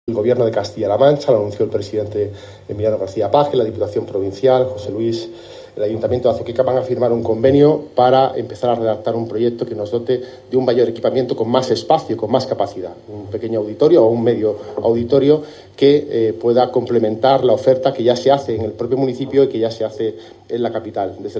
Declaraciones del presidente de las Cortes Regionales Pablo Bellido